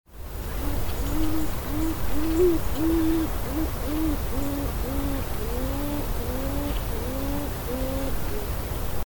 Beaver
Beavers communicate with each other via distinct whining, grunts, grumbles, and barks.
beavers-call.mp3